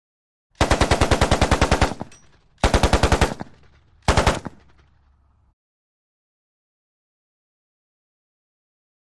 Machine Gun | Sneak On The Lot
Automatic Machine Gun Rapid Fire With Distinctive Bolt And Band Action.